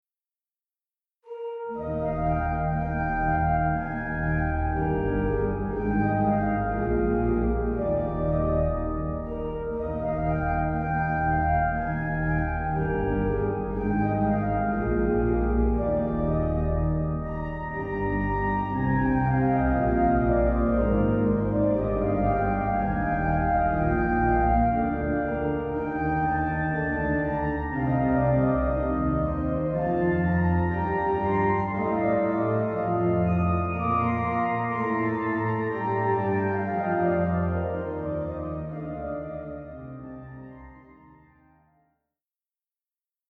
●リードオルガン 楽譜(手鍵盤のみで演奏可能な楽譜)